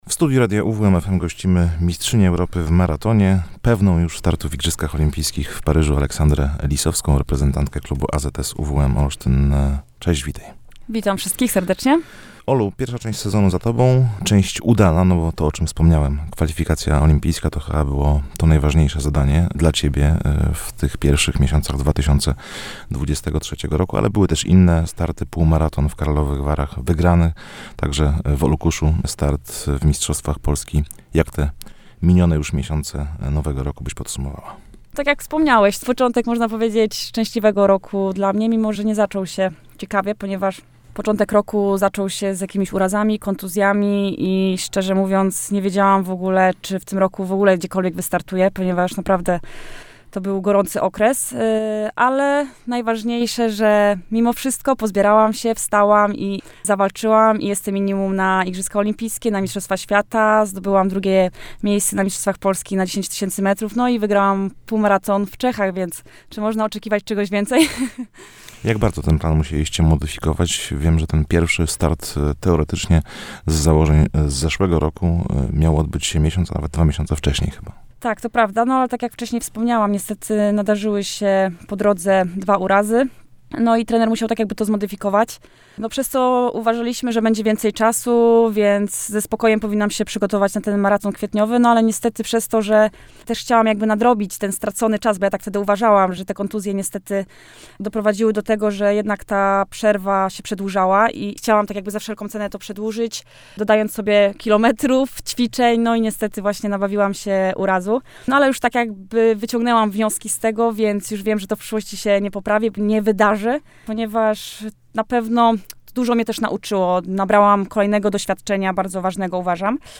Medal Mistrzostw Europy to już dla mnie przeszłość. Widzę na horyzoncie kolejne cele. Mam kolejne marzenia! – mówiła w studiu Radia UWM FM Aleksandra Lisowska.